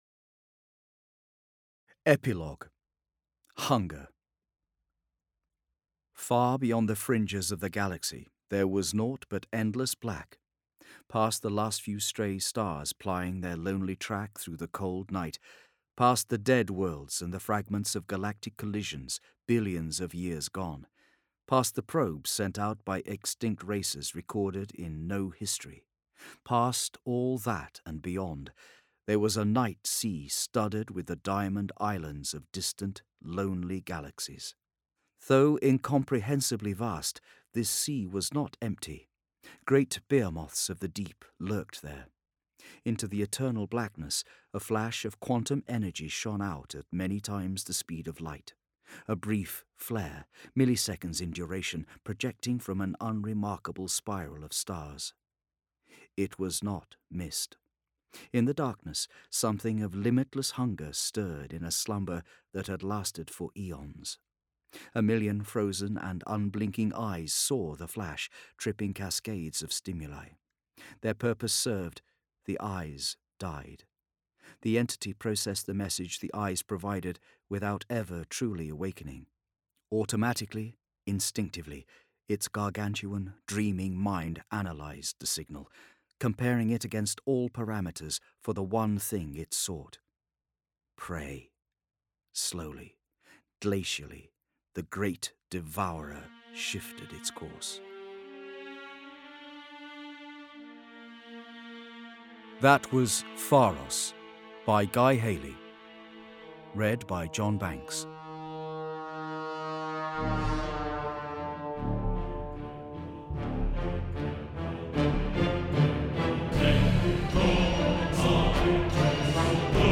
Games/MothTrove/Black Library/Horus Heresy/Audiobooks/The Complete Main Series/HH 31-40/34